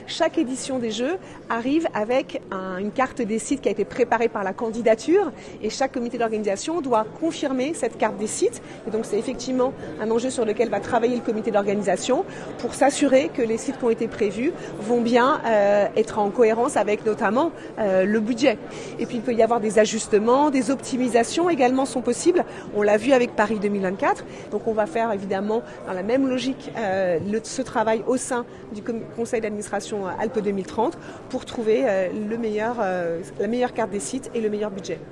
Ces jeux s’étaleront des Aravis à Courchevel en passant par Nice et les Hautes Alpes. Des sites qui pourront encore faire l’objet d’ajustement comme le confirme la ministre des sports Marie Barsacq interrogée cet après midi.